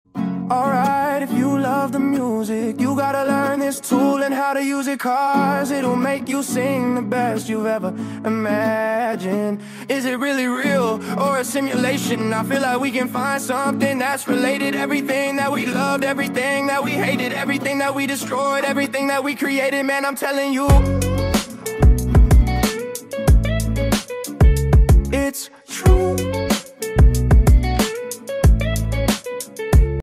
I filmed myself freestyling over my guitar riff
now you have the same song with a completely reimagined voice